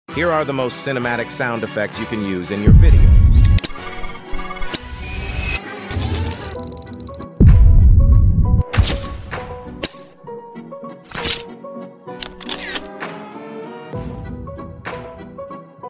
Most Cinematic Sound Effects Pt.01 sound effects free download